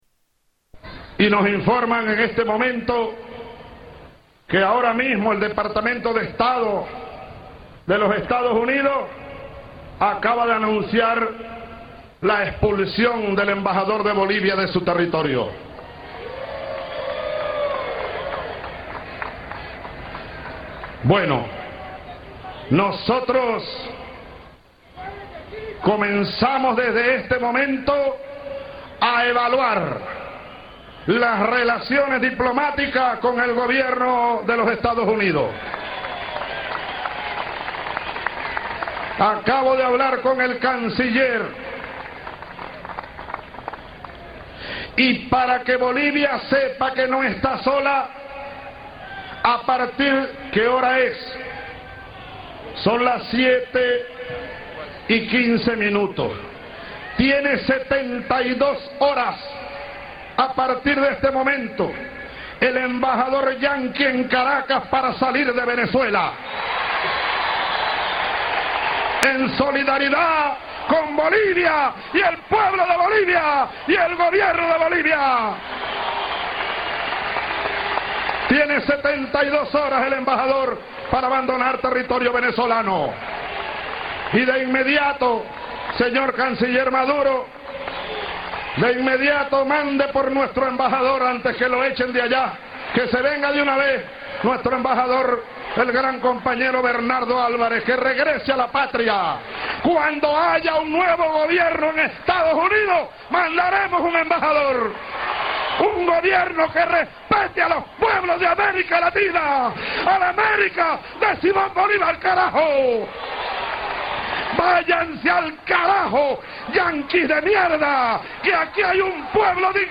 Tags: Historical Top 10 Censored News Stories 2009 Censored News Media News Report